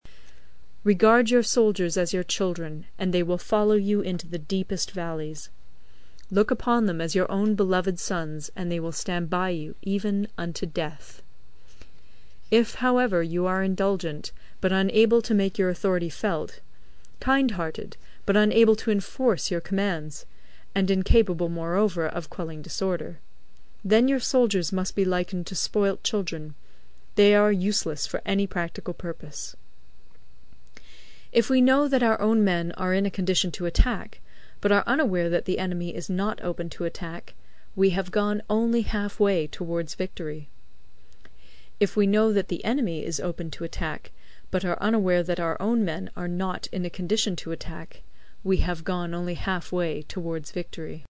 有声读物《孙子兵法》第60期:第十章 地形(4) 听力文件下载—在线英语听力室